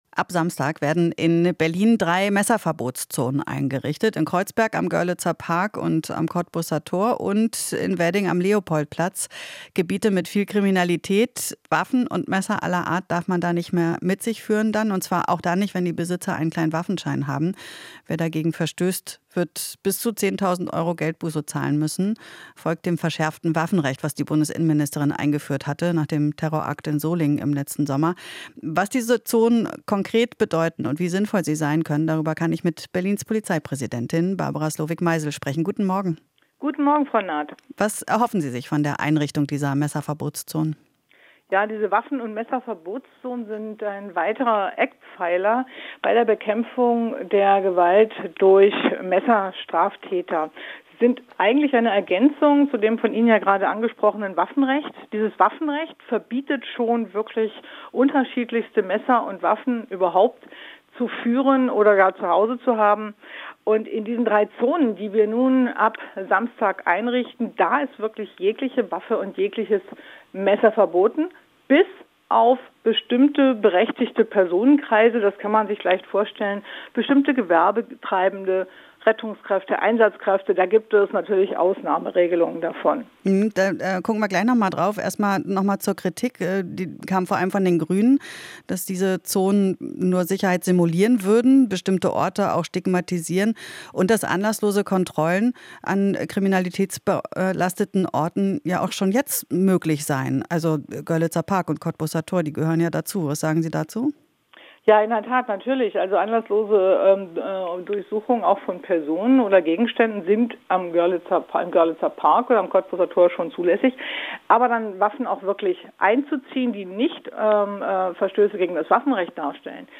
Interview - Polizeipräsidentin Slowik Meisel verteidigt Messerverbotszonen